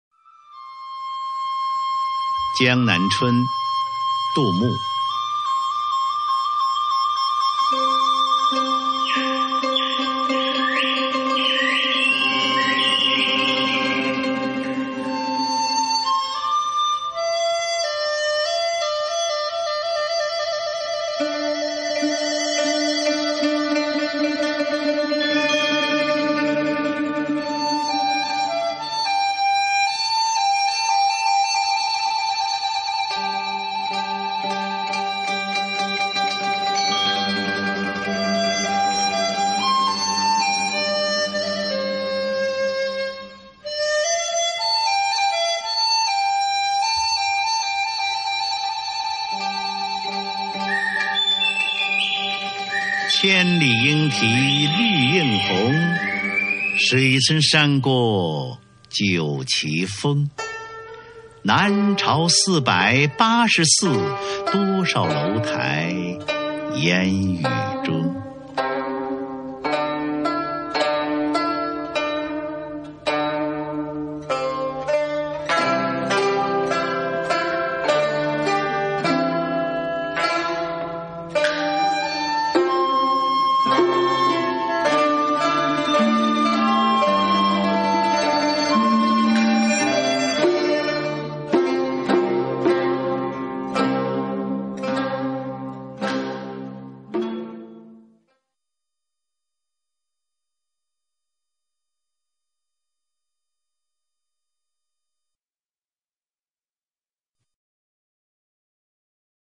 首页 视听 经典朗诵欣赏 群星璀璨：中国古诗词标准朗读（41首）